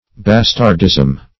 Bastardism \Bas"tard*ism\, n.